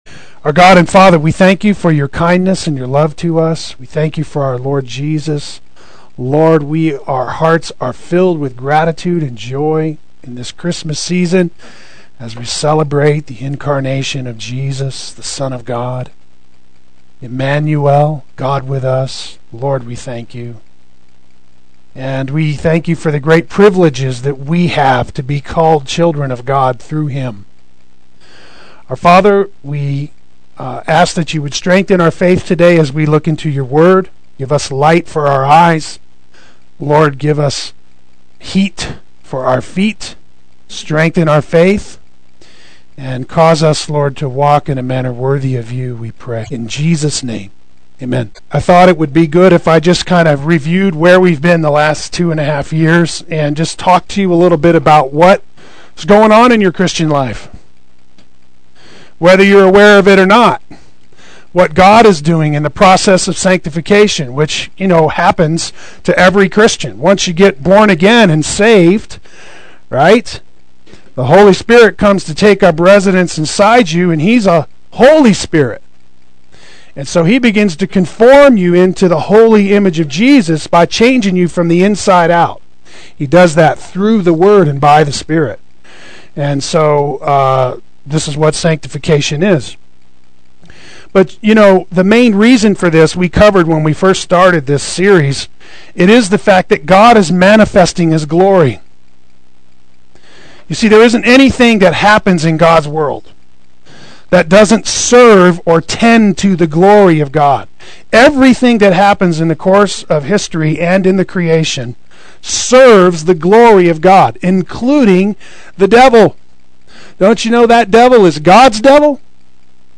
Review of In His Image Adult Sunday School